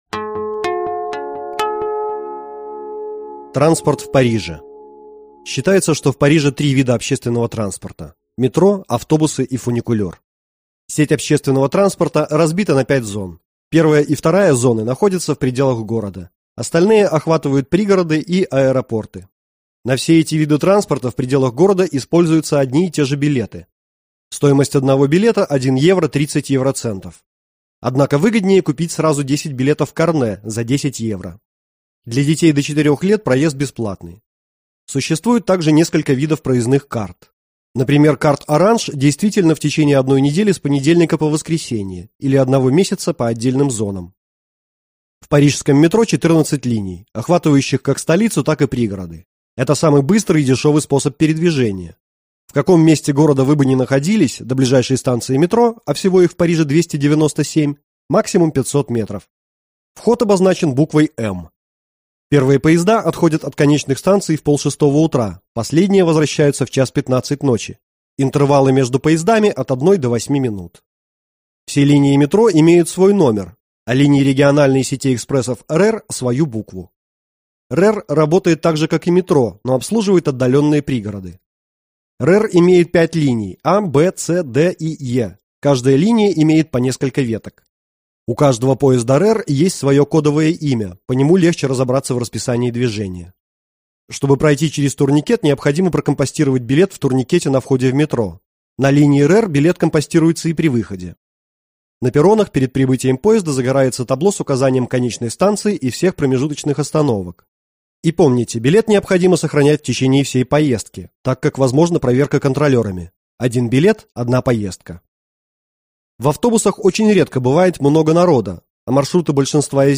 Аудиокнига Путеводитель по Парижу | Библиотека аудиокниг